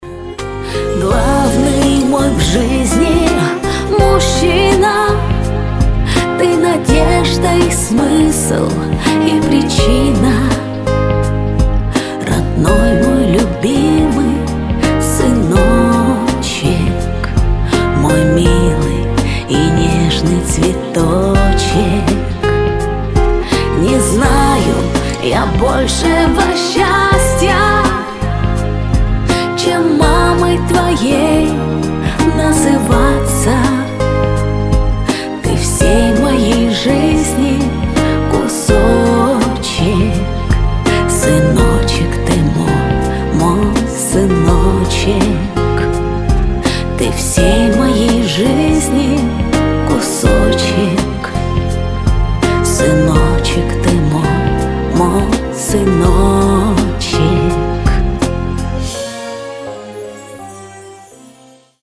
Спокойные рингтоны
Нежные рингтоны
Рингтоны шансон